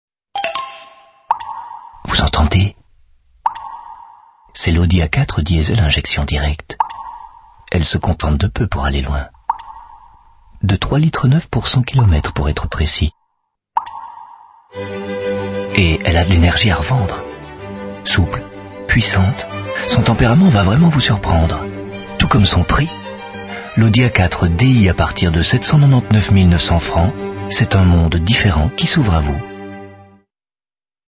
Pubs Radio: